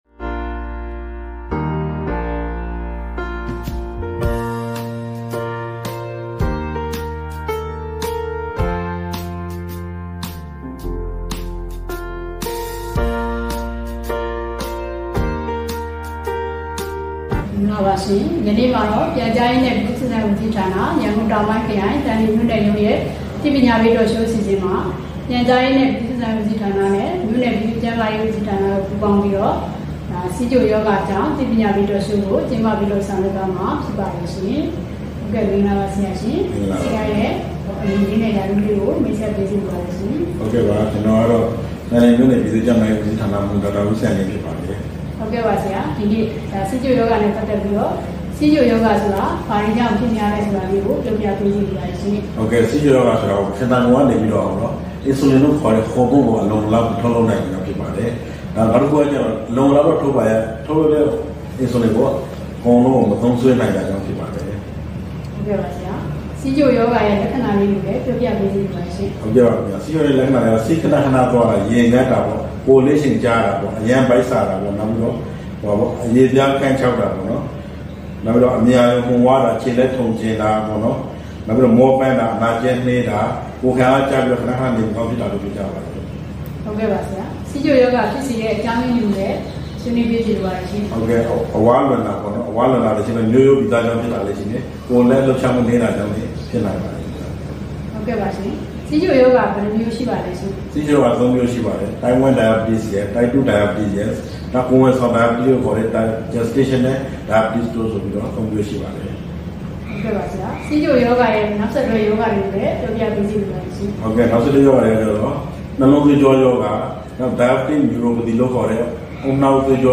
သန်လျင်မြို့၌ဆီးချိုရောဂါအကြောင်းသိကောင်းစရာ Talk Show ပြုလုပ်